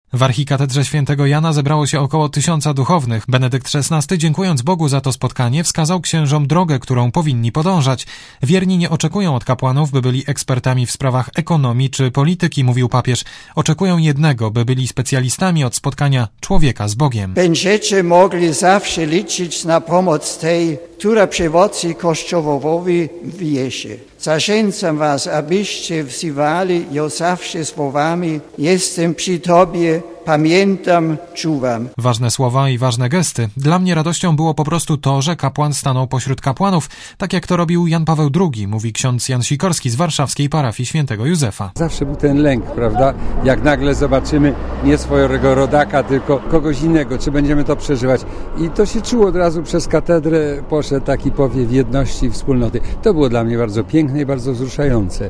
O roli kapłaństwa i o tym, kim powinien być ksiądz, mówił Benedykt XVI w Archikatedrze Św. Jana Chrzciela na warszawskiej Starówce.
Relacja
papierz_w_katedrze.mp3